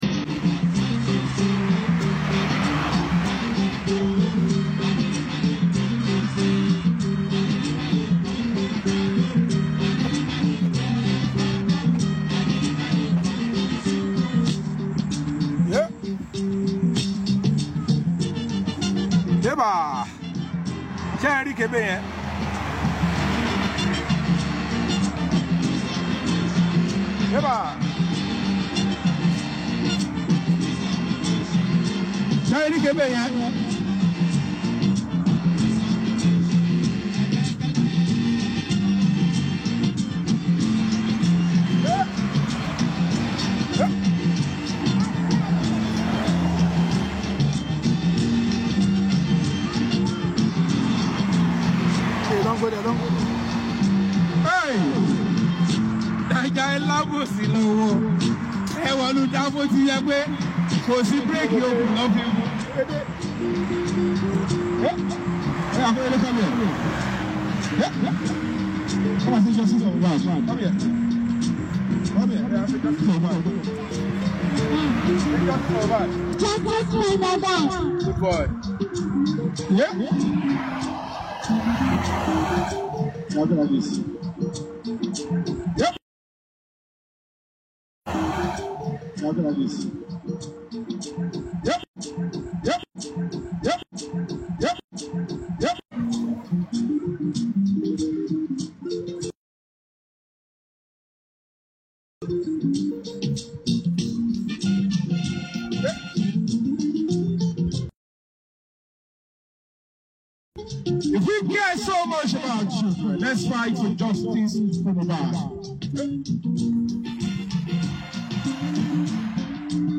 Yomi Fabiyi arrives Nigeria, protest alone on the street of Abuja for Mohbad